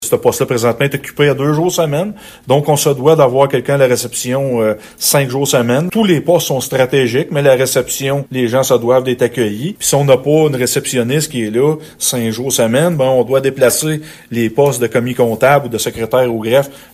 Le maire de Gracefield, Mathieu Caron, en dit davantage :